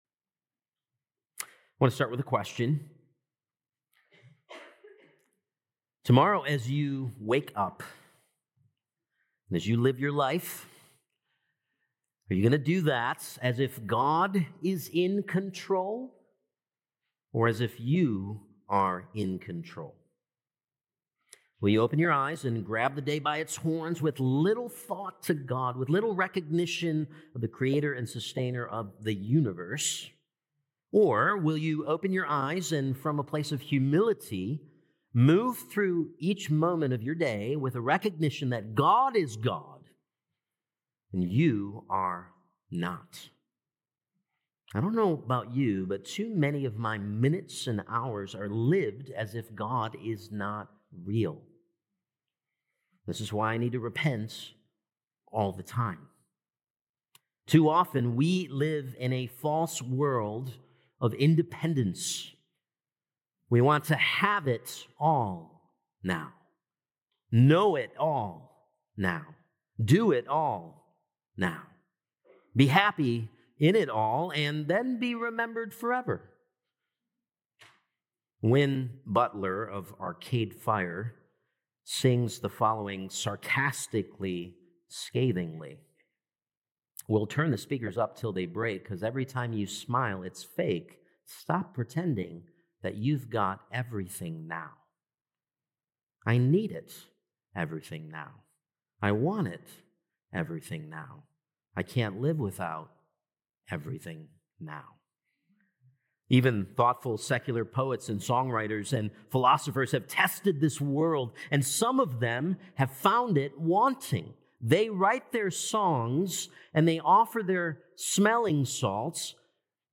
Apr 13th Sermon | Ecclesiastes 9:1-10